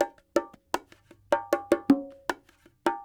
44 Bongo 06.wav